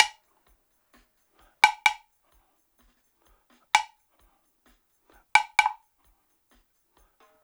129-BLOCK2.wav